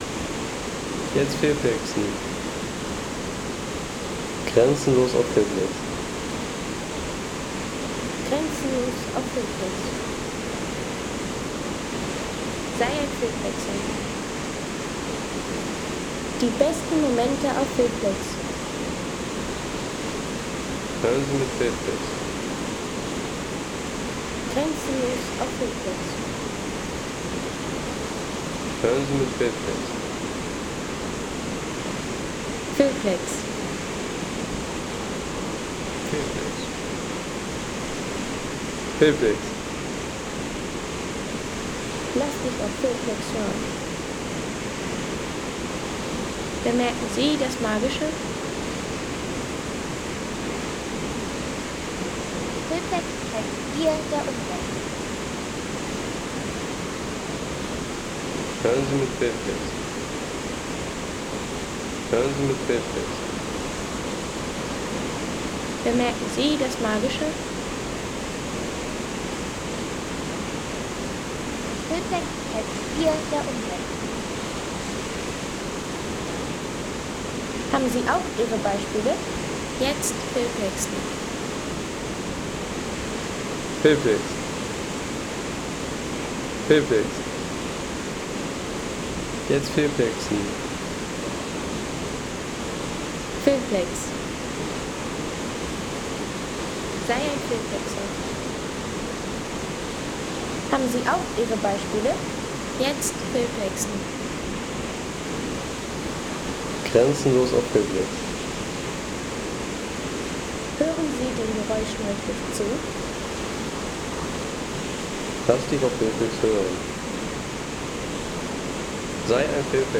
Stuibenfall Wasserfall Sound aus Umhausen | Feelplex
Stuibenfall-Sound mit alpiner Wasserfall-Atmosphäre
Erholungsgenuss für alle, wie z.B diese Aufnahme: Stuibenfall-Ionenplattform
Ein lebendiger Wasserfall-Sound aus Umhausen mit frischer Gischtstimmung und Naturkulisse für Film, Postkarten und atmosphärische Szenen.